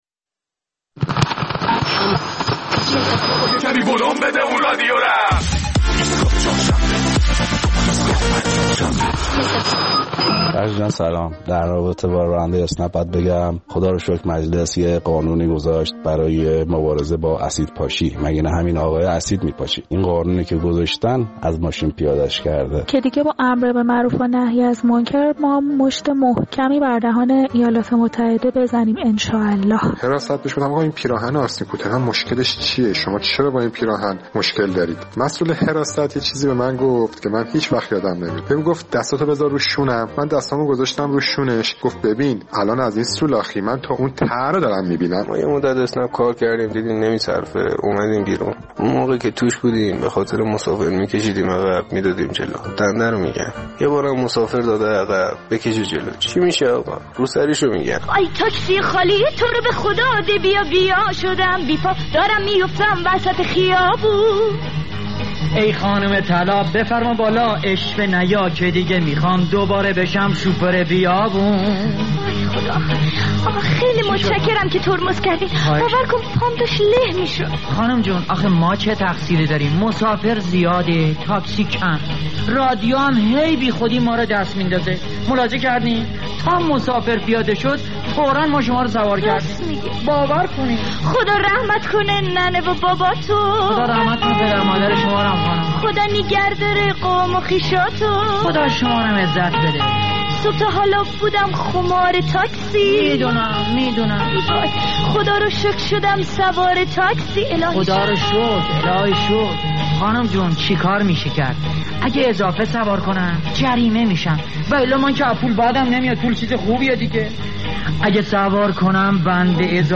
در این برنامه درباره تجربیات امر به معروف و نهی از منکری مخاطبین ایستگاه فردا در رابطه با تاکسی‌های اینترتی پرسیدیم و نظراتشان را درباره اتفاقات اخیر روی داده بین یک راننده اسنپ و یک خانم و حواشی آن می‌شنویم.